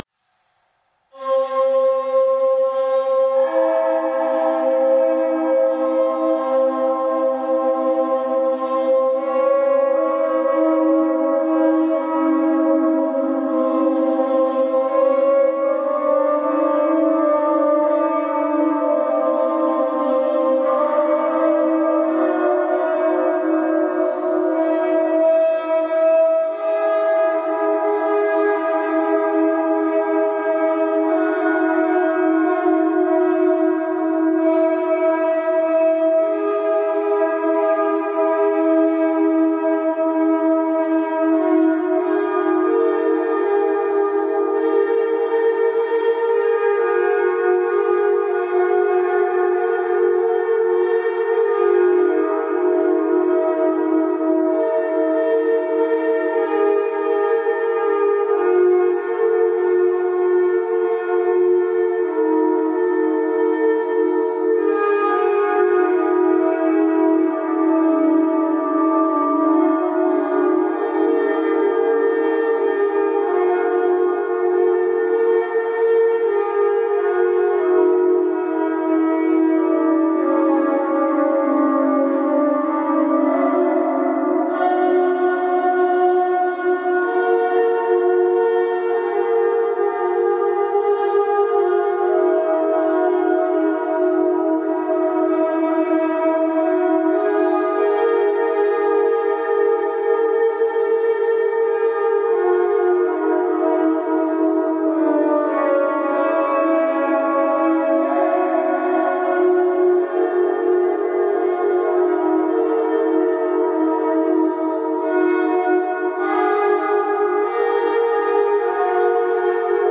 背景抽象的声音
标签： 背景 圆润的 抽象的 声音
声道立体声